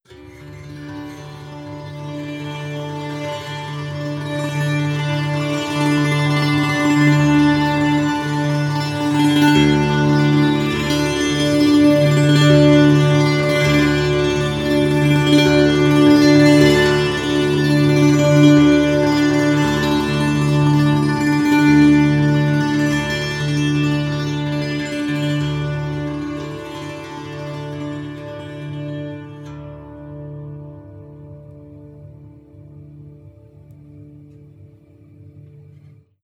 • therapy monochord.wav
therapiemonochord_MG6.wav